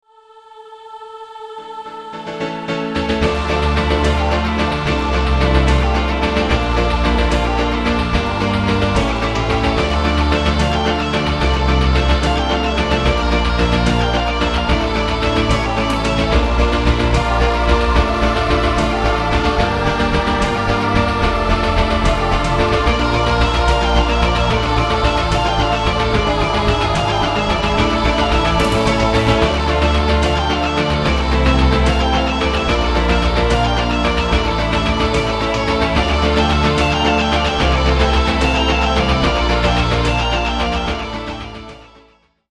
お遊びなので、パート数も最低限で、音色やボリュームも適当です。
（ヘッドホンで音量調整しているのでスピーカーで聴くと低音が小さいはずです・・・）
028 　ゲーム風〜defgfed〜（Dm） 07/02/08